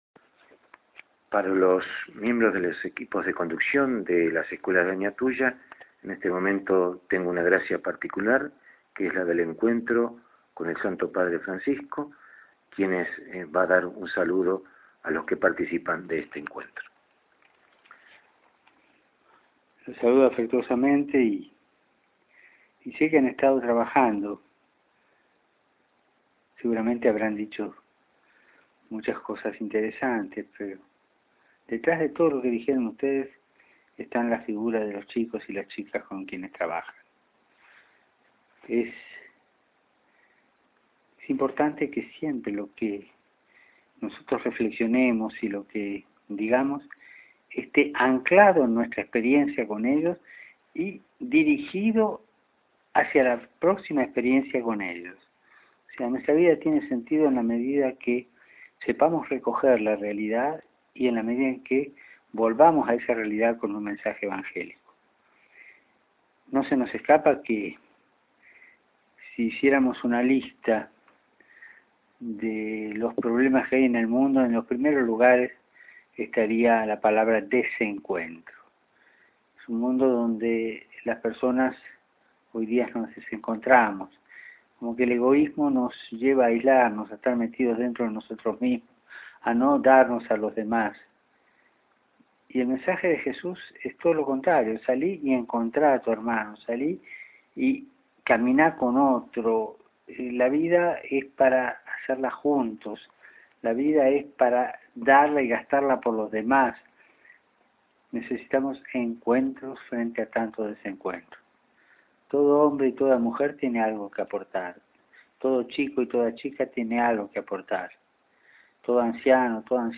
Escuchá el mensaje que nuestro Papa Francisco les dirigió a los directivos de los colegios de la diócesis de Añatuya.
Monseñor Adolfo Uriona lo grabó con su celular cuando se reunió con el Santo Padre en el Vaticano, el pasado mes de Enero de 2014.